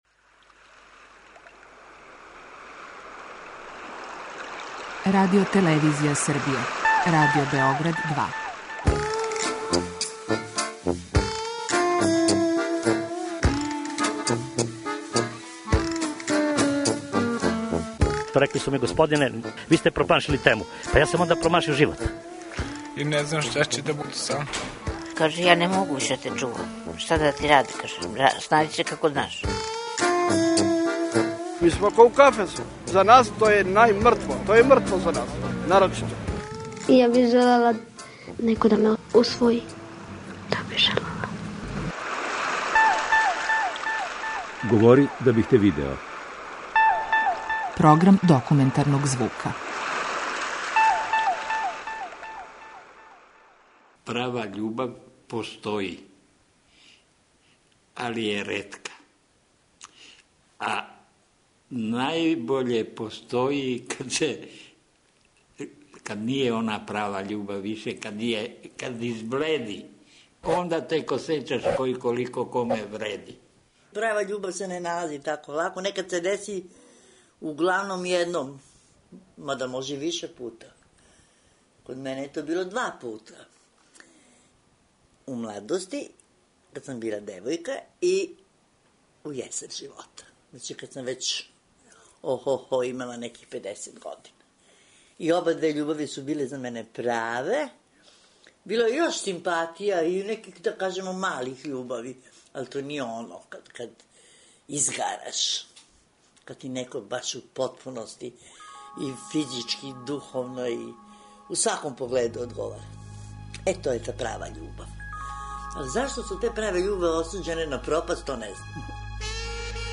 Документарни програм
Баке и деке у осмој и деветој деценији живота, говоре о сећањима на први пољубац и никад заборављене љубави.